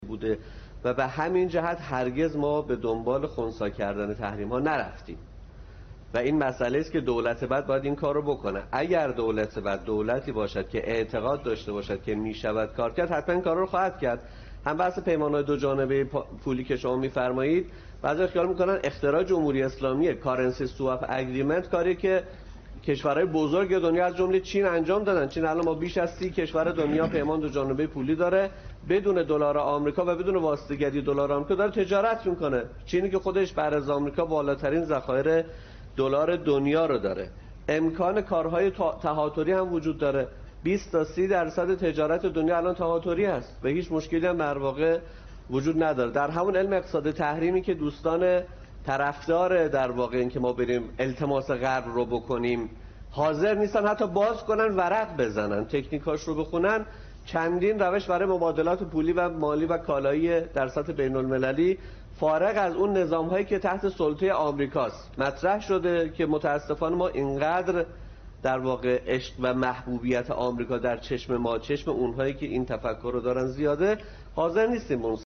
عبدالملکی، اقتصاددان